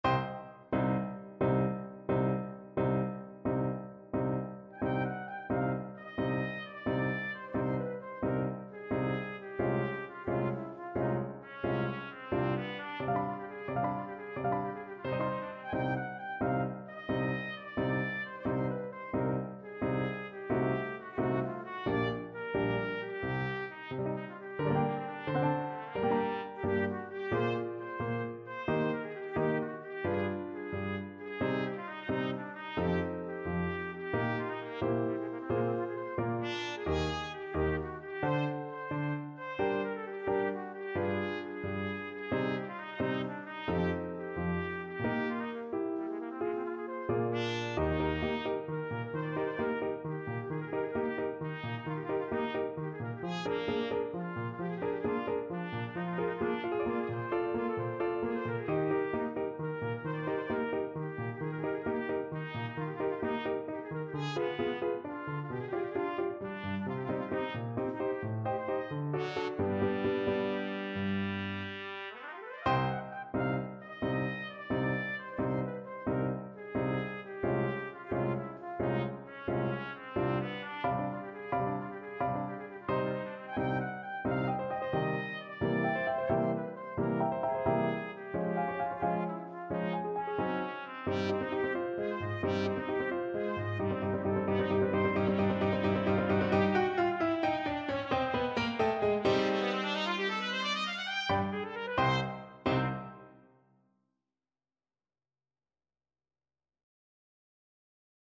6/8 (View more 6/8 Music)
Classical (View more Classical Trumpet Music)